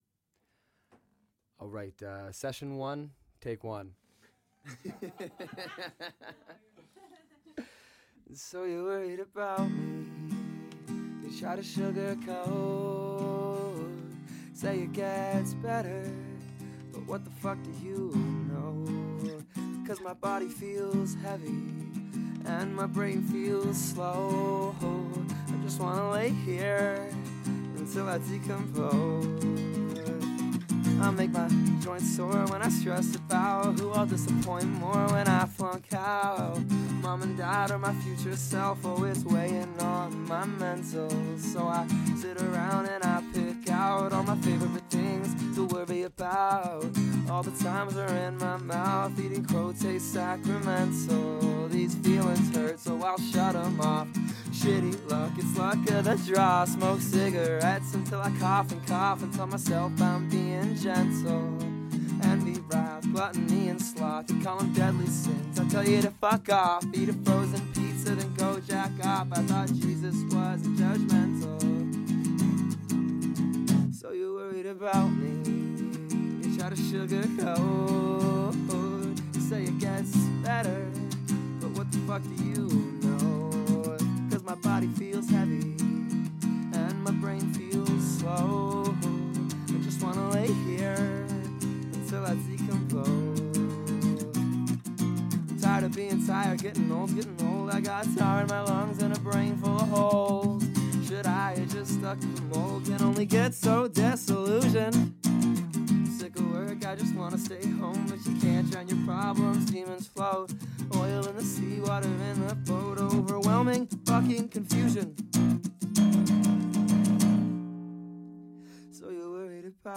it's not mixed or mastered yet